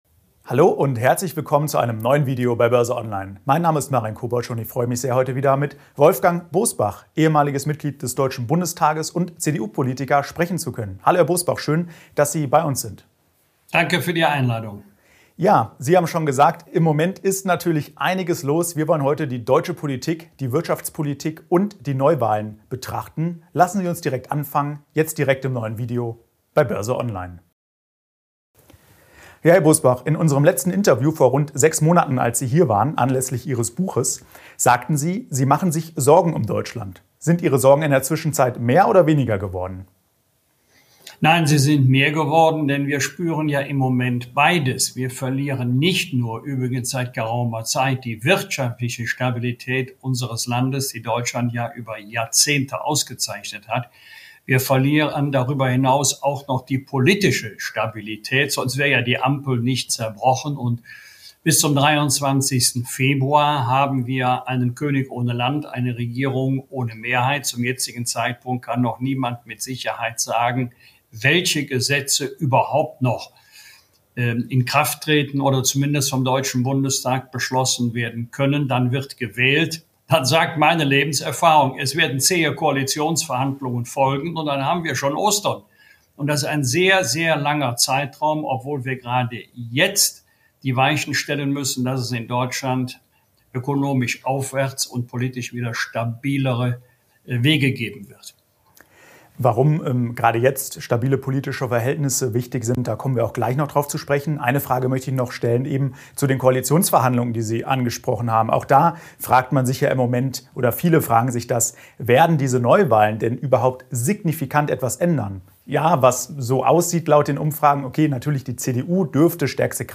Gelingt so das Wirtschaftswunder 2.0 in Deutschland? BÖRSE ONLINE im Talk mit Politik-Experte Wolfgang Bosbach (CDU) ~ BÖRSE ONLINE Podcast